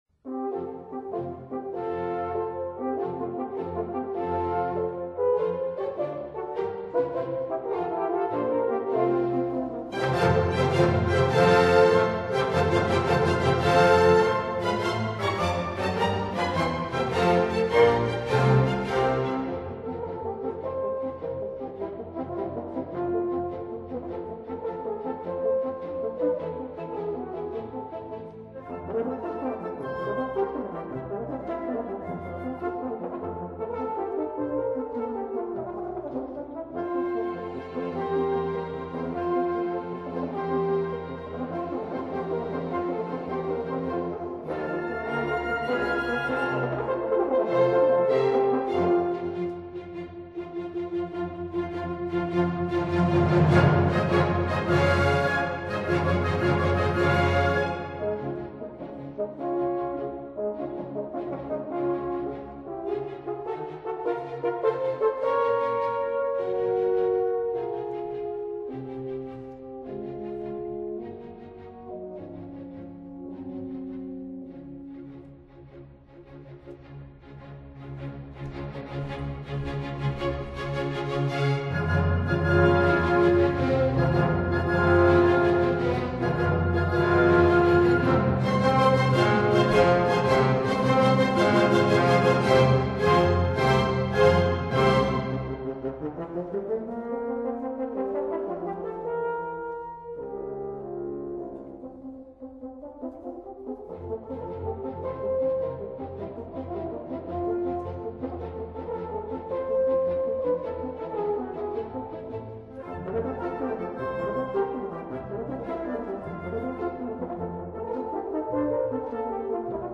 5. 雙圓號協奏曲 Op.45